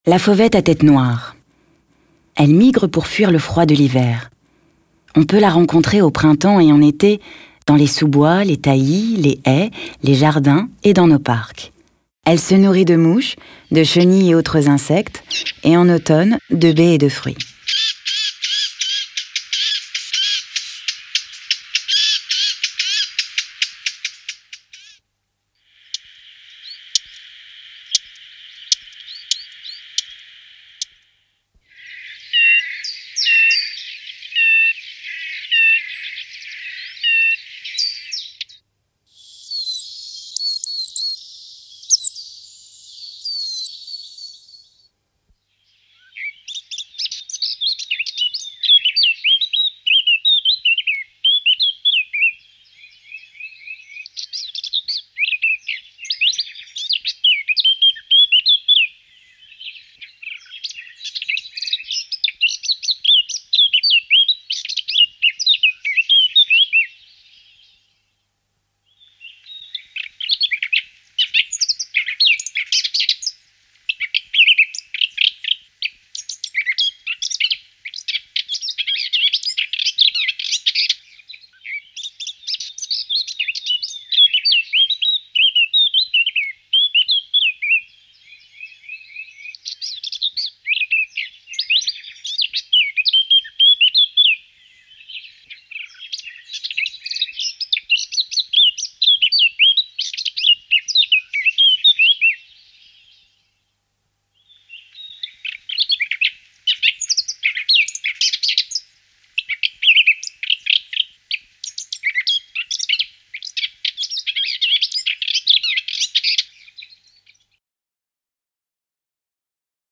Index of / stock ancien/6/09_le_carnaval/sons oiseaux
fauvette.mp3